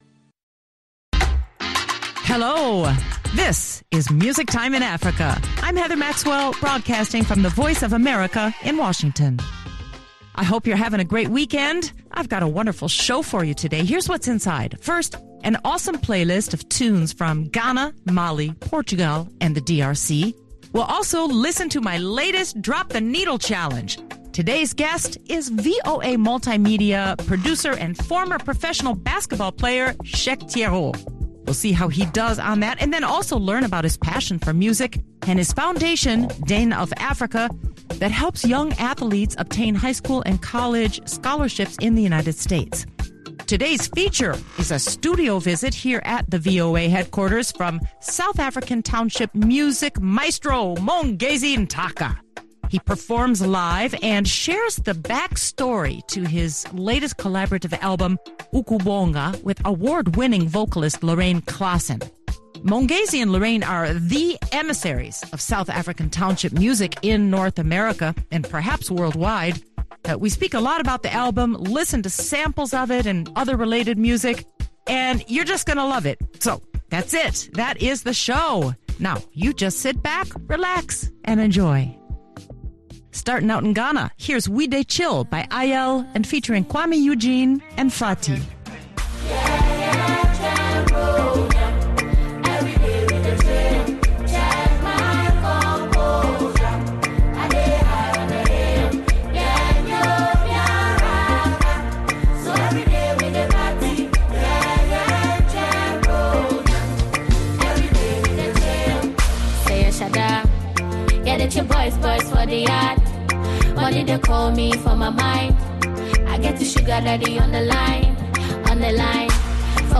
performs live
backup vocals